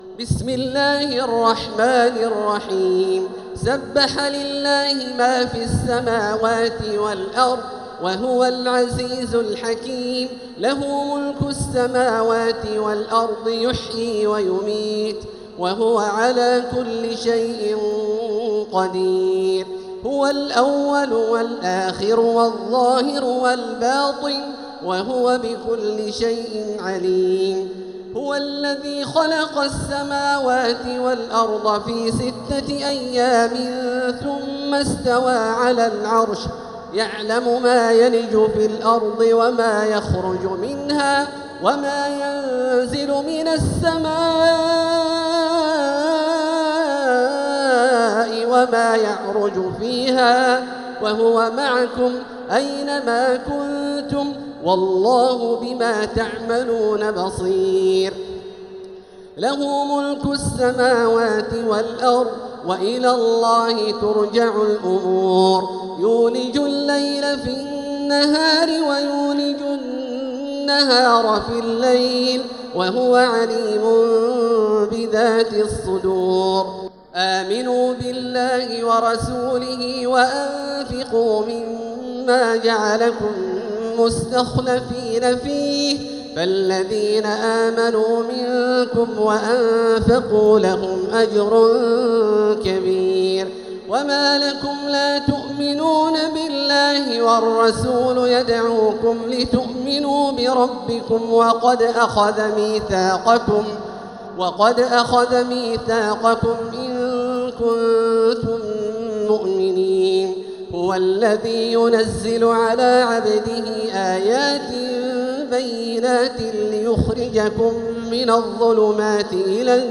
سورة الحديد | مصحف تراويح الحرم المكي عام 1446هـ > مصحف تراويح الحرم المكي عام 1446هـ > المصحف - تلاوات الحرمين